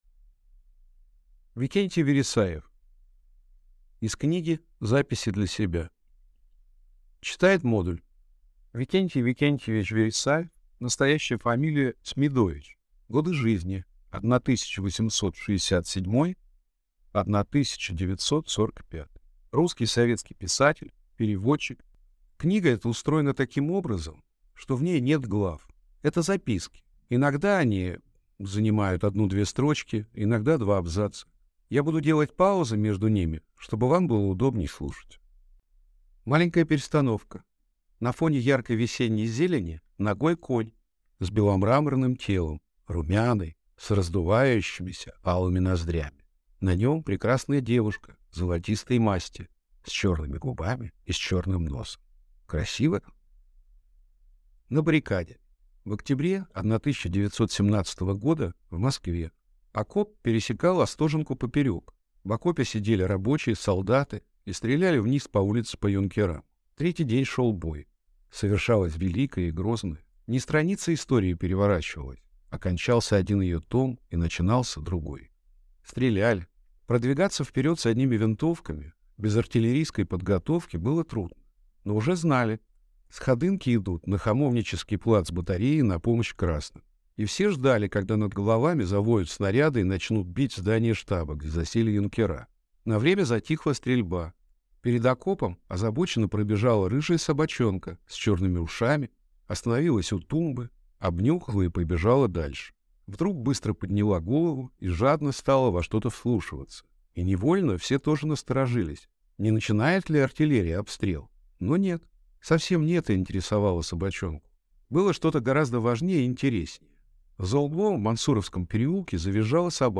Аудиокнига Из книги «Записи для себя» | Библиотека аудиокниг